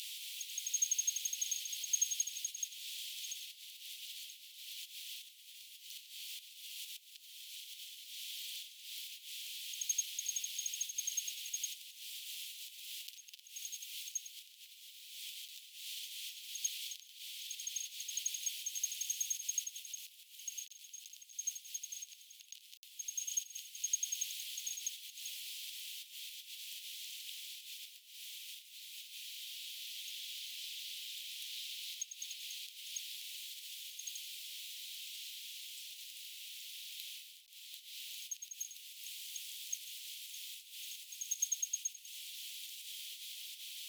joku_mekaaninen_aani.mp3